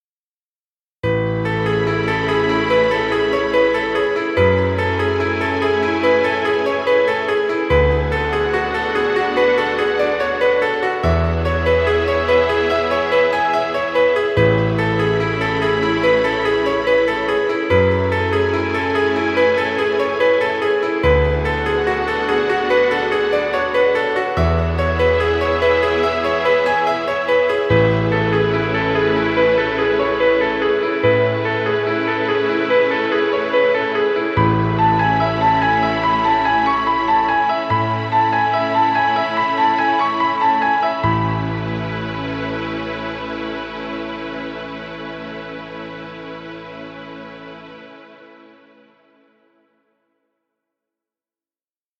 Romantic music. Background music Royalty Free.
Stock Music.